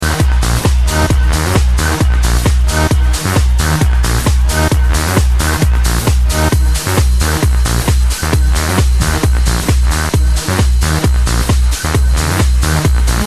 Bassline sounds very nice.
it's a benassi-like
sounds like something from benassi